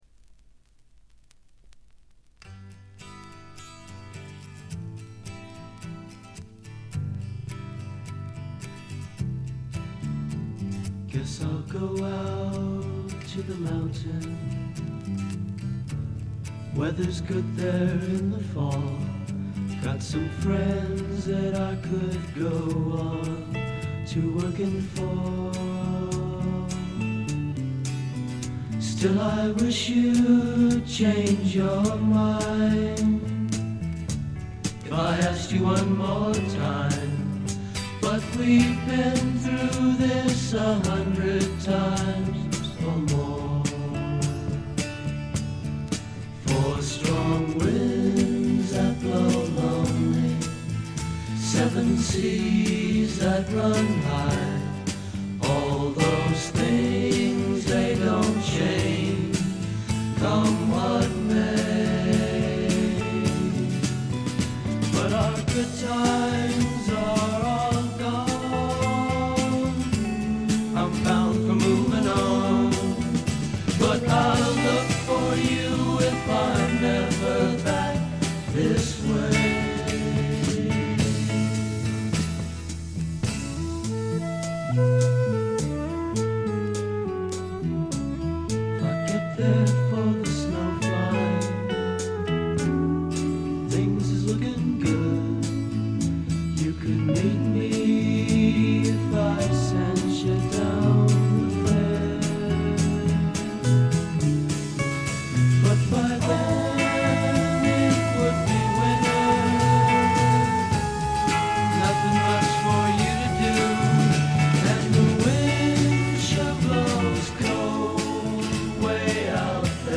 軽微なチリプチ程度。
試聴曲は現品からの取り込み音源です。